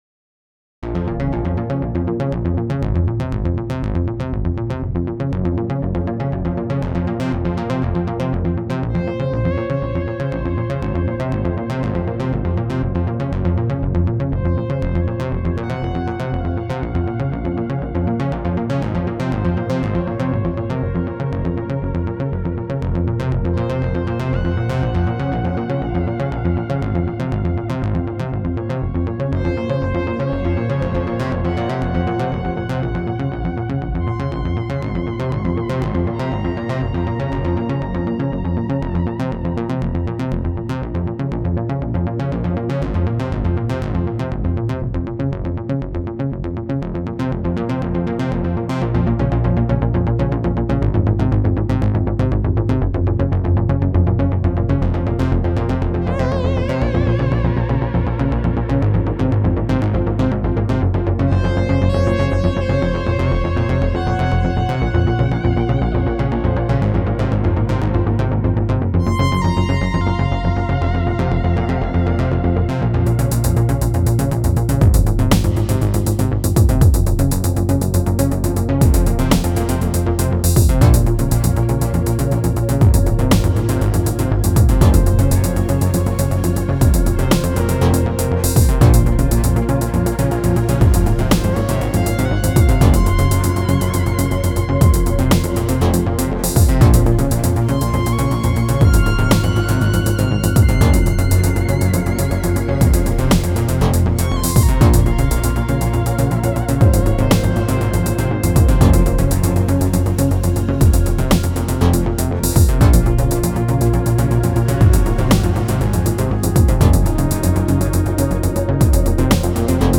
Sequential Prophet-5 & Prophet-10 neu / Rev-4
TROCKEN MONO ORDENTLICH NASS GEDÖDEL IM ZUSAMMENHANG